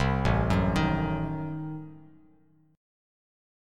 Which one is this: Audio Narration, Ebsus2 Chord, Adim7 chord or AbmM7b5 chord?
Adim7 chord